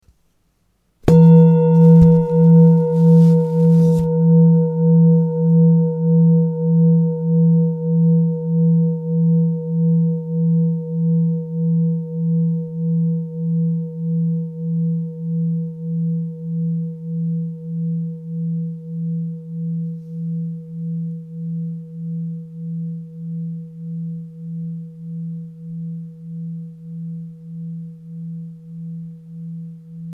Klangschale Alte tibetische Universalschale 985g KM80-450-KK
ALTE TIBETISCHE KLANGSCHALE - UNIVERSALSCHALE
Grundton: 166,91 Hz
1. Oberton: 484,55 Hz